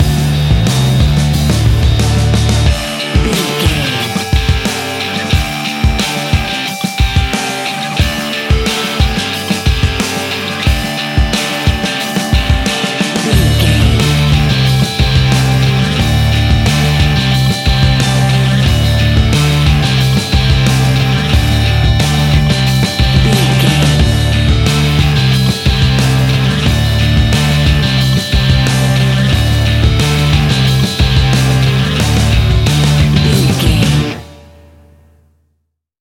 Ionian/Major
D
energetic
driving
aggressive
electric guitar
bass guitar
drums
hard rock
blues rock
heavy drums
distorted guitars
hammond organ